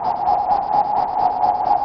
RI_RhythNoise_130-03.wav